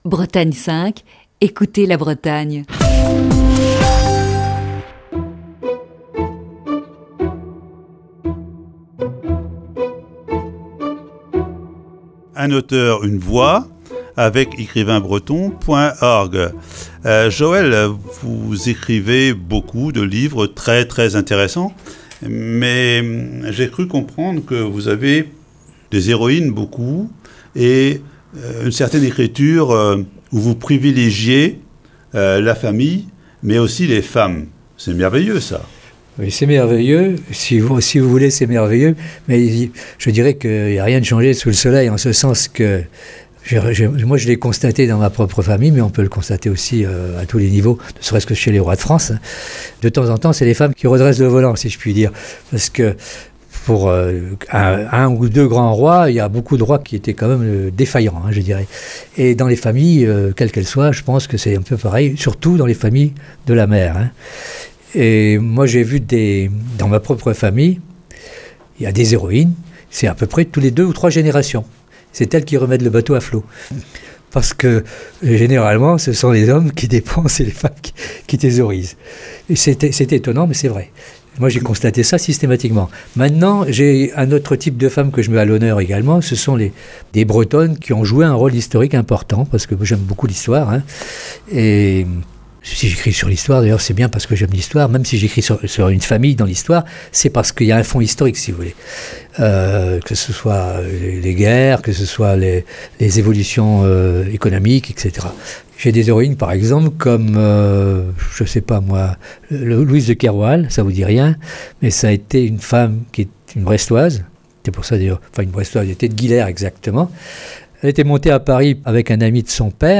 Voici ce jeudi, la quatrième partie de cette série d'entretiens.